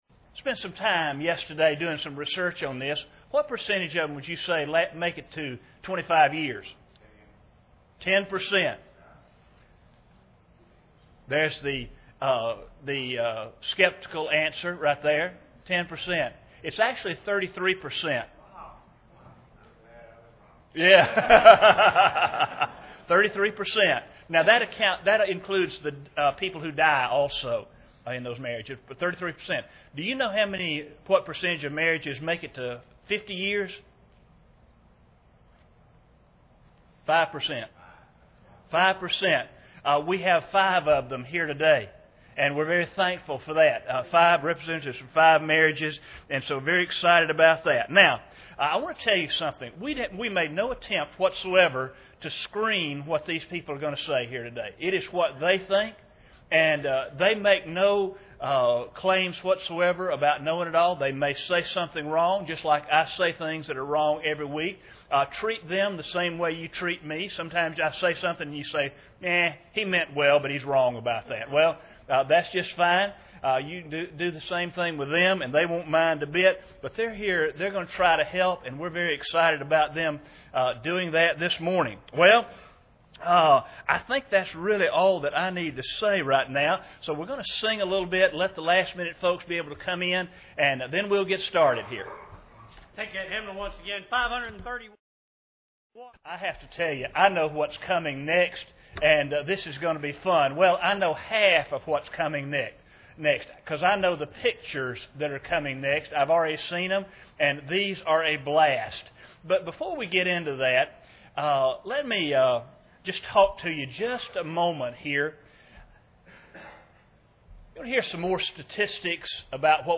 Today was a very special celebration of long lasting marriages at Crooked Creek. Various couples who have been married for 25 years or more shared their advice to other couples and those who will one day be married.
Service Type: Sunday Morning